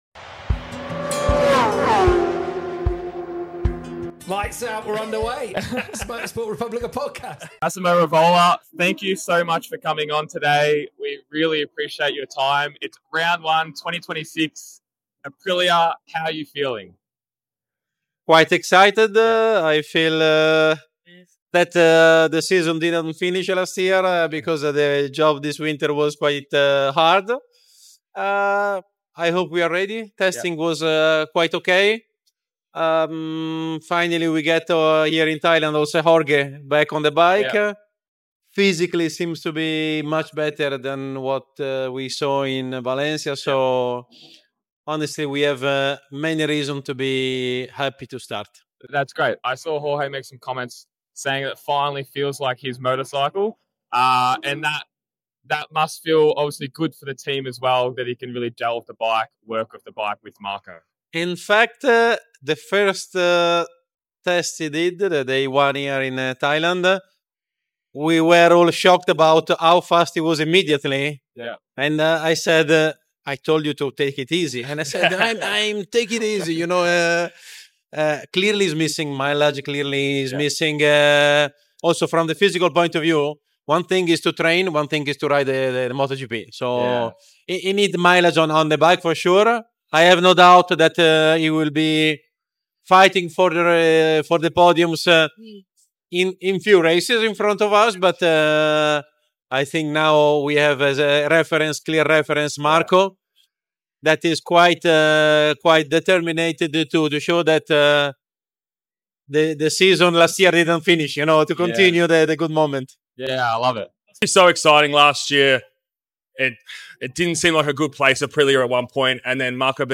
at the Thai GP. They talk Aprilia’s future, current contracts, where they are heading plus some talks about the rider market and possibly a transfer window.